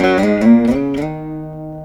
129  VEENA.wav